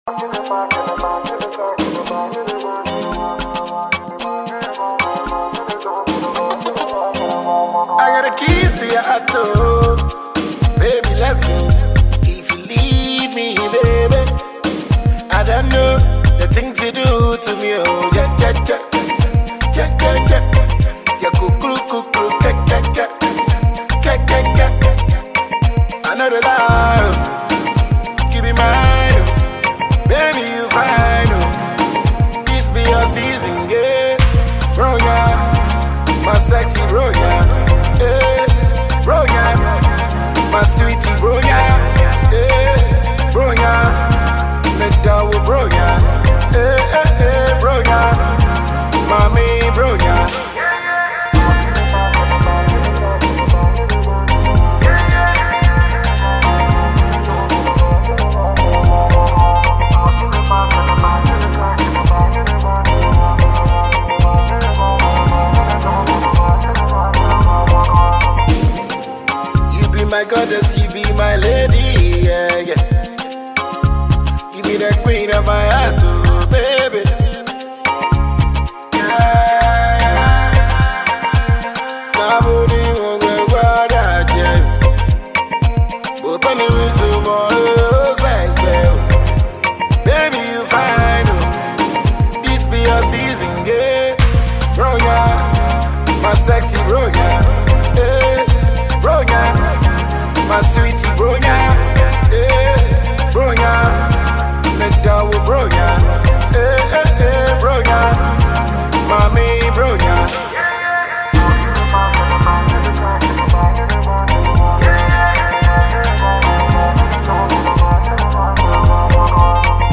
Xmas tune